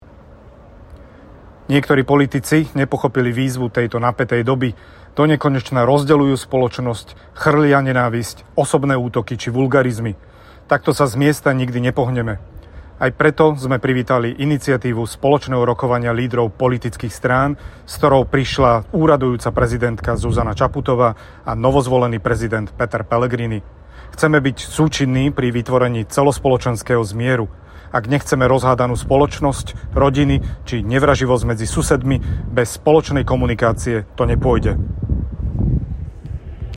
Zvuková nahrávka predsedu SaS Branislava Gröhlinga (2)